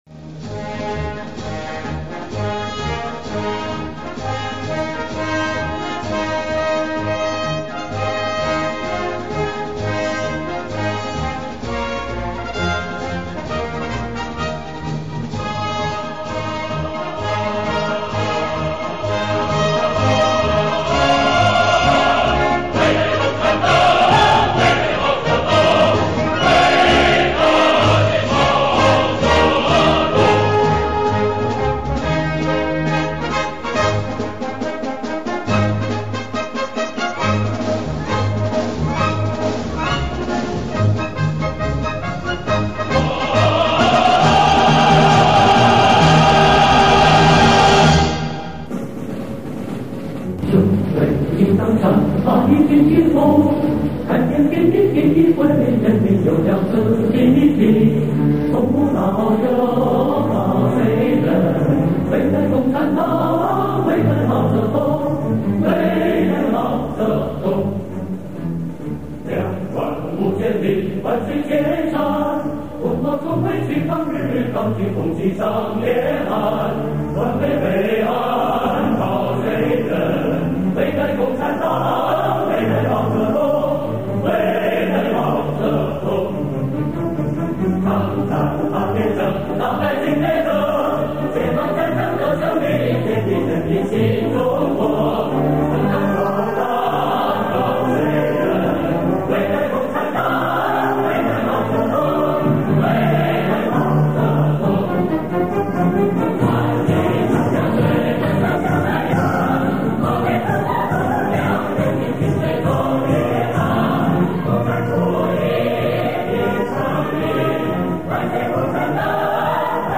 [31/7/2014]献给八一建军节 合唱 人民军队忠于党